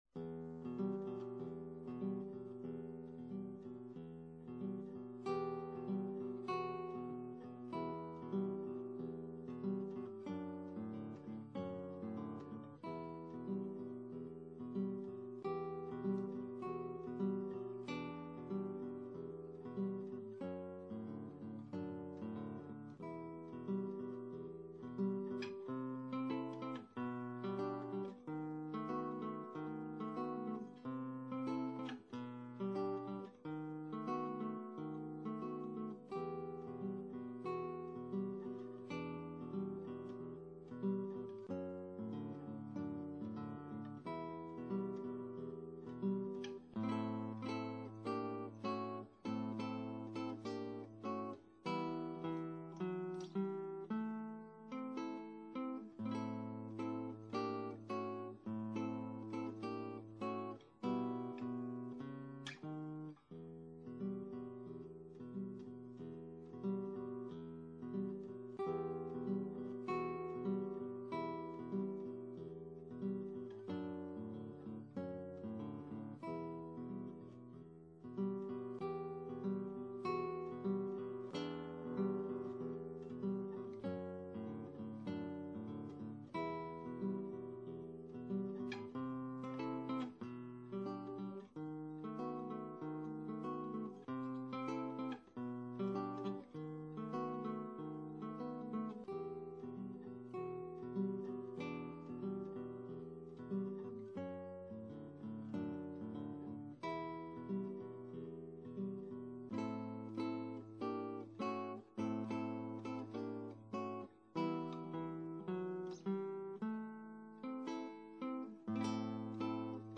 Original Works for Classical Guitar